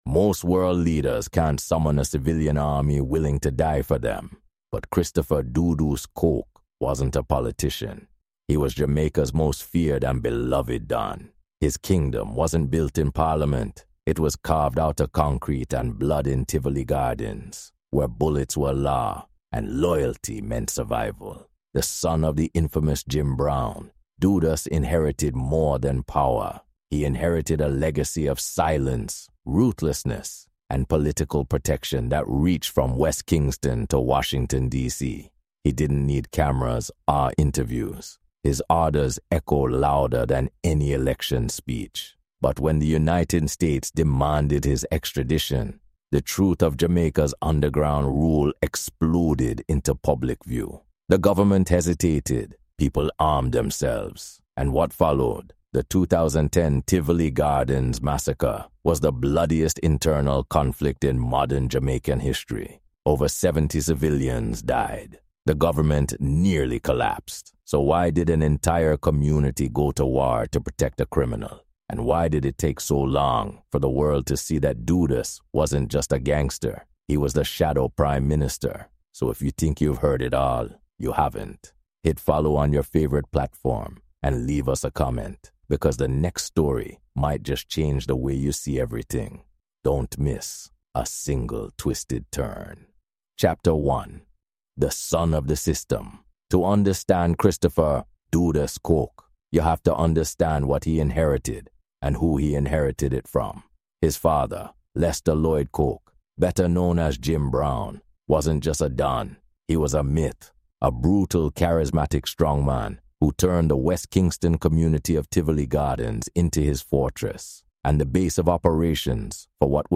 Journey from Kingston’s Tivoli Gardens—where Christopher “Dudus” Coke ruled like a king—to the U.S. courtroom that finally silenced him. History experts, travel experts, and Jamaican music insiders unpack how colonization, Cold-War politics, and dancehall culture forged a cartel that was loved by its community yet feared worldwide.
Expect untold truths, first-hand stories, and a soundtrack steeped in reggae, dancehall, and the enduring spirit of the Caribbean region.